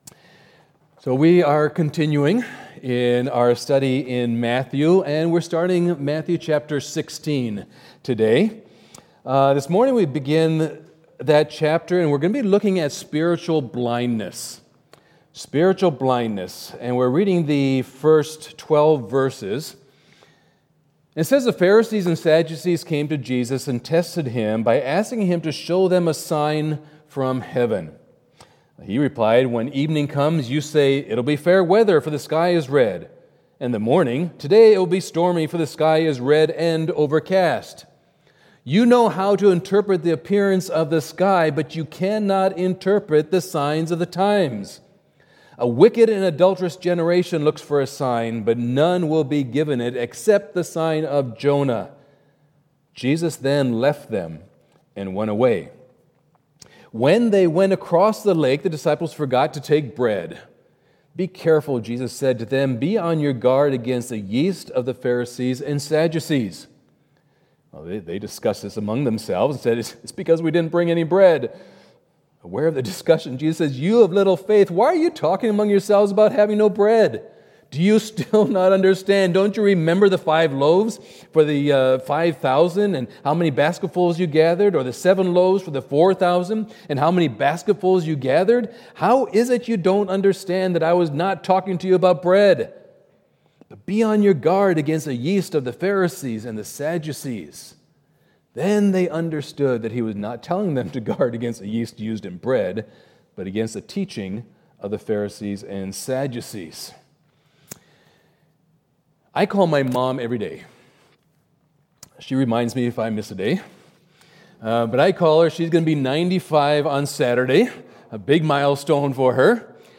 2020 Dealing with Spiritual Blindness Preacher